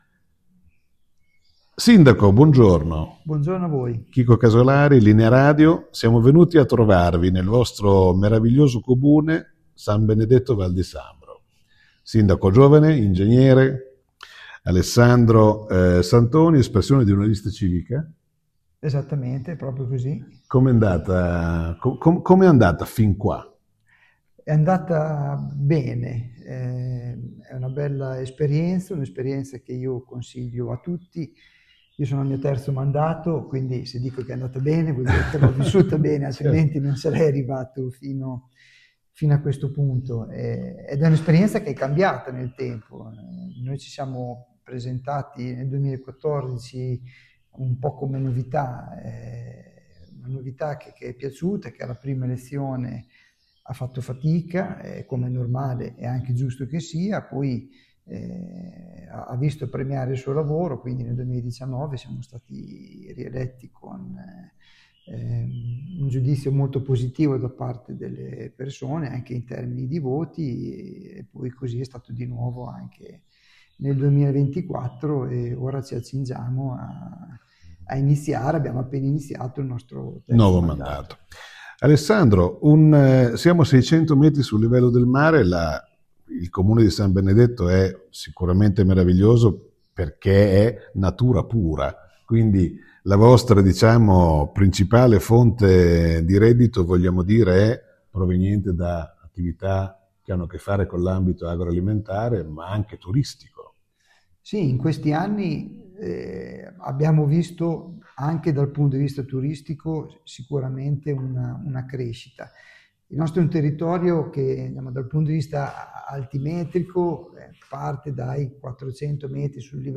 “Voglio Live”, intervista al Sindaco Alessandro Santoni
Intervista al Sindaco di San Benedetto Val di Sambro Alessandro Santoni